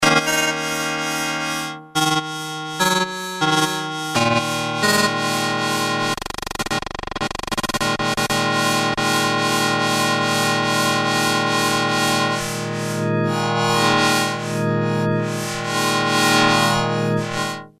demo glissando test
Class: Synthesizer